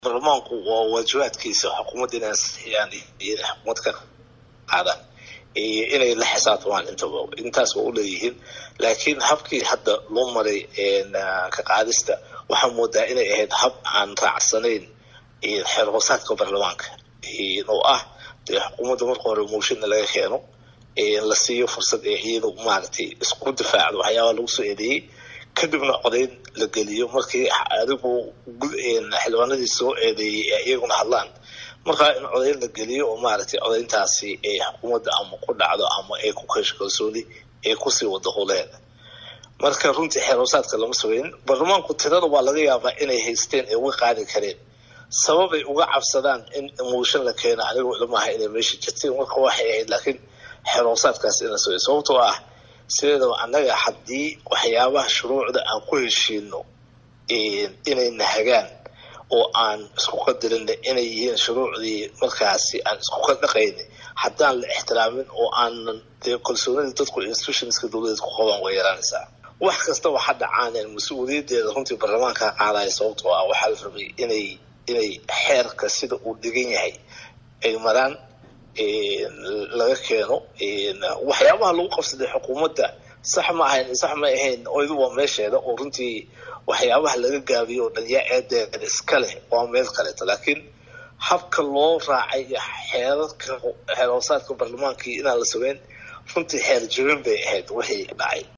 Ra’iisal Wasaarihii hore Soomaaliya Cumar Cabdi Rashiid Cali shar ma’arke ayaa ka hadley isbeddelka siyaasadeed ee ka dhacay magaalada Muqdisho kadib markii xilka laga qaadey Raysalwasaare Xassan Cali Kheyre.